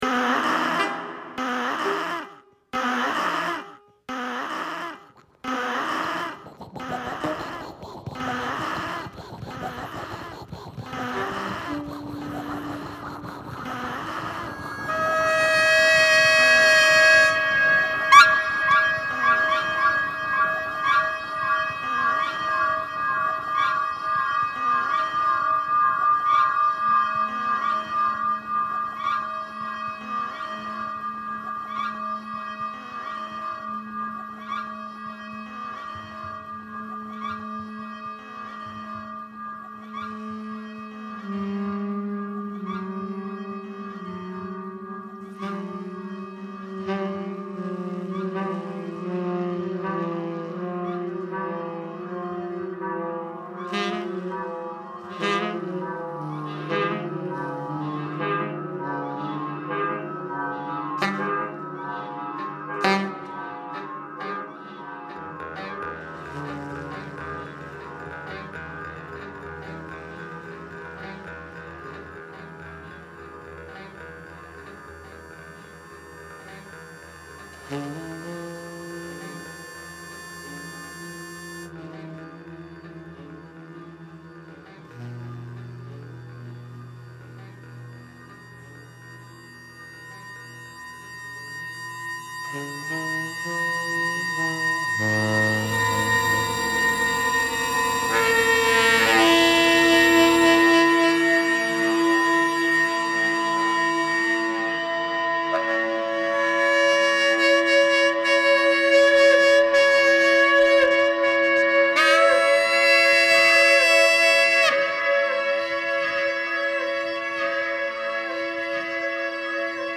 VCS 3, électronique, voix
saxophone ténor